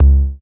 雅马哈C S15模拟鼓单打" bd3
描述：雅马哈模拟单声道有2个VCO，2个ENV，2个多模滤波器，2个VCA，1个LFO
标签： 模拟 bassdrum CS-15 雅马哈
声道立体声